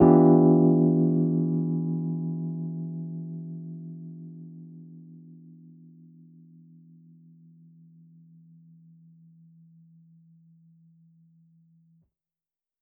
JK_ElPiano3_Chord-Em7b9.wav